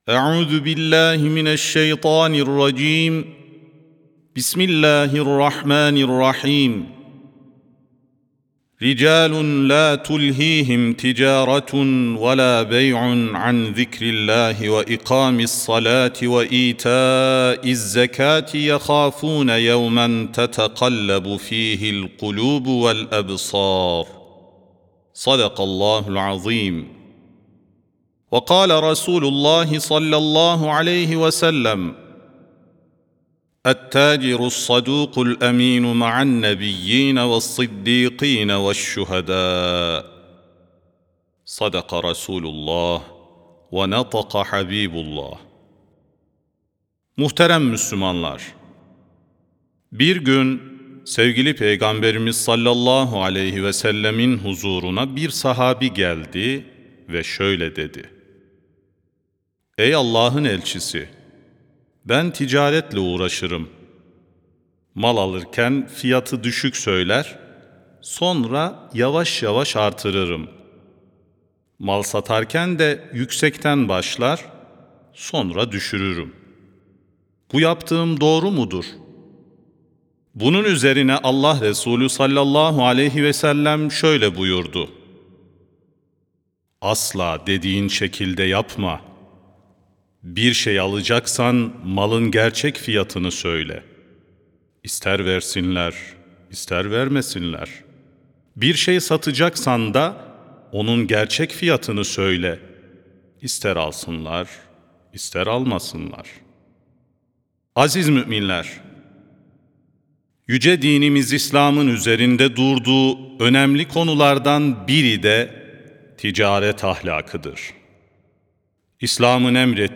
19 Eylül 2025 Tarihli Cuma Hutbesi
Sesli Hutbe (Peygamberimiz (s.a.s) ve Ticaret Ahlakı).mp3